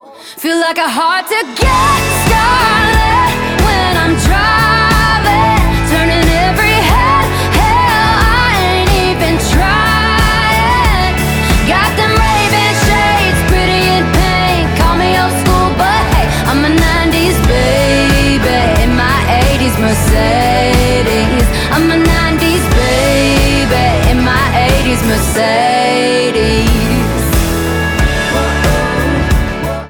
• Country
American country music singer